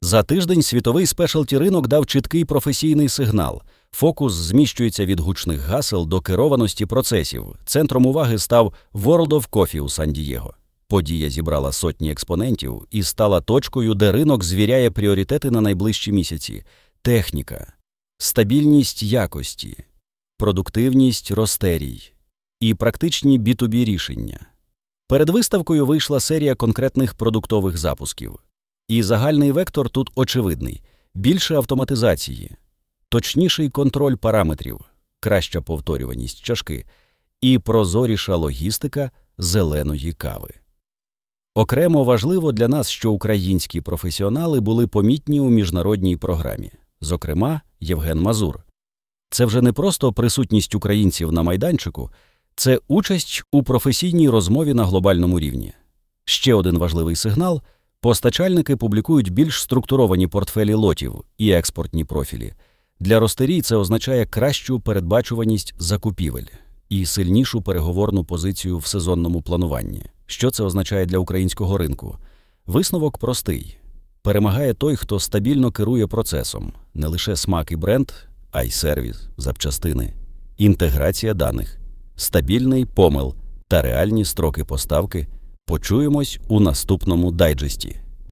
Озвучка дайджесту